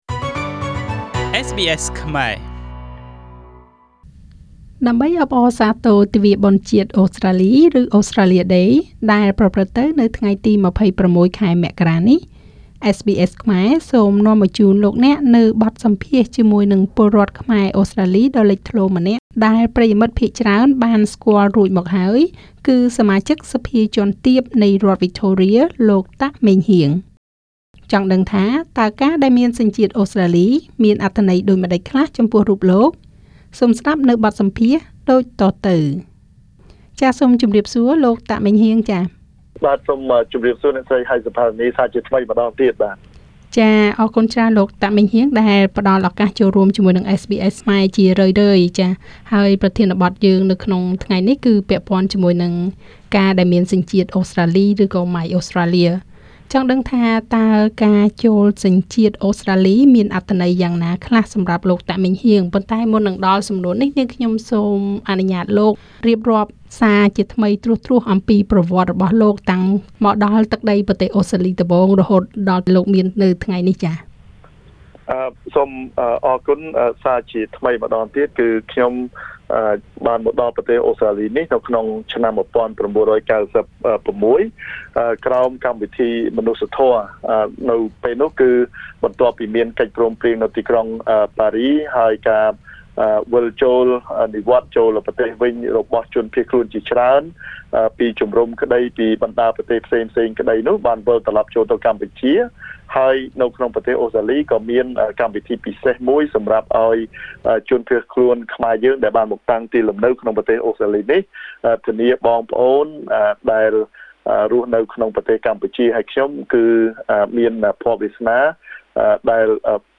ដើម្បីអបអរសាទរទិវាបុណ្យជាតិអូស្រ្តាលី ឬ Australia Day ដែលនឹងប្រព្រឹត្តិទៅនៅថ្ងៃទី២៦ មករានេះ SBS ខ្មែរ សូមនាំមកជូនលោកអ្នកនូវបទសម្ភាសន៍ជាមួយពលរដ្ឋខ្មែរអូស្រ្តាលីដ៏លេចធ្លោម្នាក់ ដែលប្រិយមិត្តភាគច្រើនបានស្គាល់រួចមកហើយ នោះគឺ សមាជិកសភាថ្នាក់ទាបនៃរដ្ឋវិចថូរៀ លោក តាក ម៉េងហ៊ាង។ តើការមានសញ្ជាតិអូស្រ្តាលីមានអត្ថន័យដូចម្តេចខ្លះចំពោះរូបលោក?
Victorian MP Meng Heang Tak at SBS studio in Melbourne Source: SBS Khmer